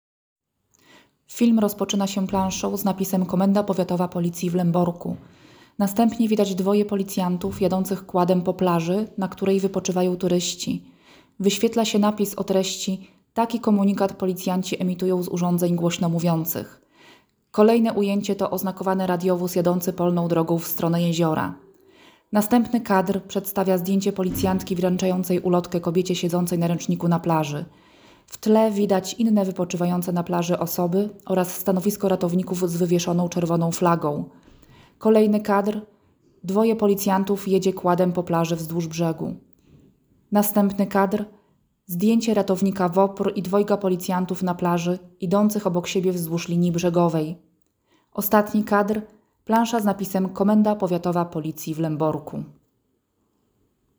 Kąpiel w miejscach niestrzeżonych czy w czasie kiedy wywieszona jest czerwona flaga, a także kąpiel po spożyciu alkoholu – to główne przyczyny utonięć. Przypominają o tym za pomocą urządzeń głośnomówiących policjanci oraz ratownicy WOPR Gniewino, patrolujący kąpieliska.
Lęborscy policjanci, którzy codziennie patrolują tereny kąpielisk i przestrzegają przed nieostrożnym korzystaniem z wody, aby dotrzeć do maksymalnej liczby plażowiczów nadają przez urządzenia głośnomówiące komunikaty głosowe z apelem o zachowanie rozsądku, wybieranie tylko strzeżonych kąpielisk i stosowanie się do poleceń ratowników, oraz powstrzymanie się od wchodzenia do wody po spożyciu alkoholu.